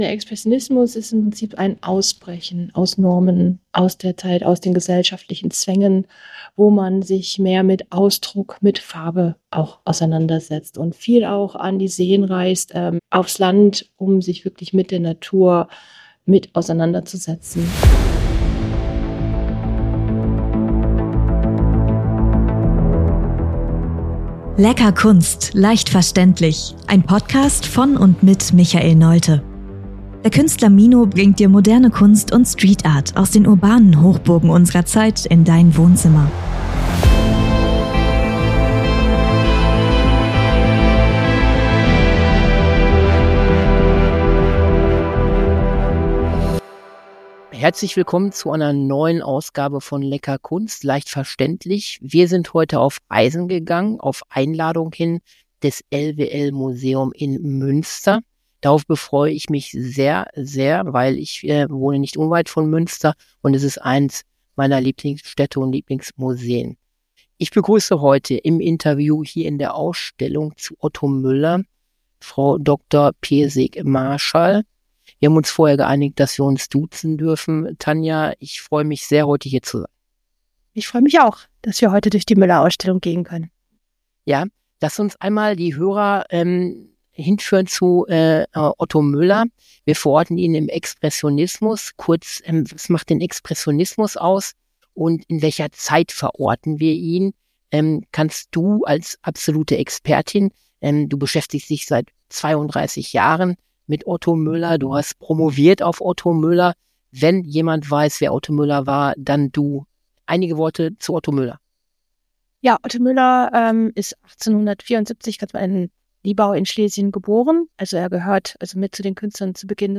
Aus dem Museum in Münster: Ein Kunstpodcast, der die Essenz von Otto Müllers Kunst und Leben einfängt.